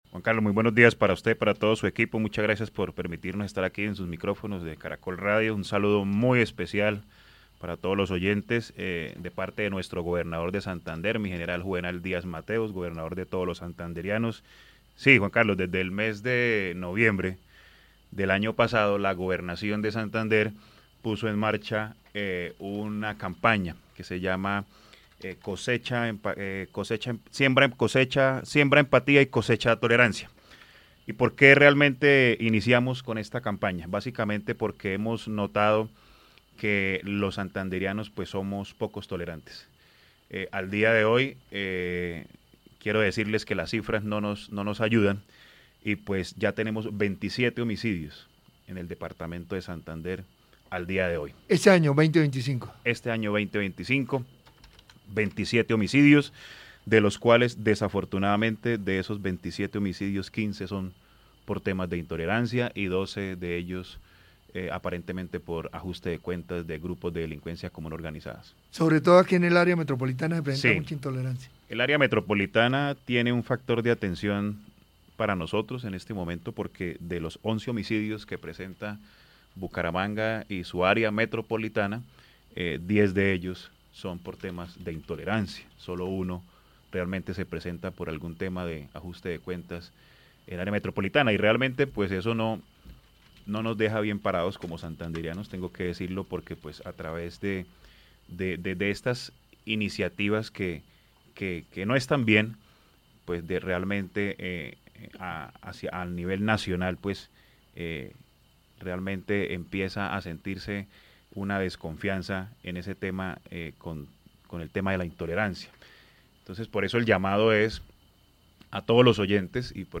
Douglas Arenas, director de Seguridad y Convivencia Ciudadana de la gobernación.